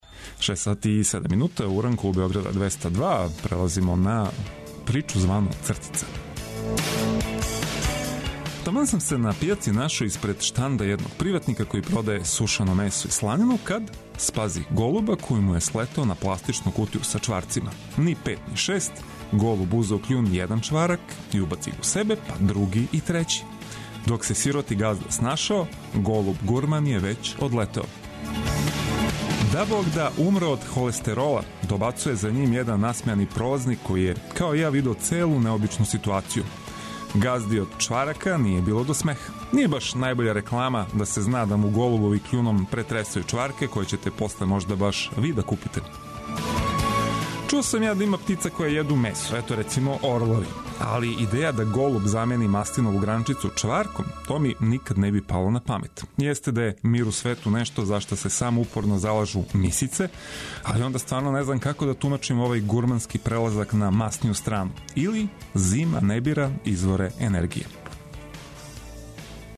Очекујте само добру музику и најнужније информације!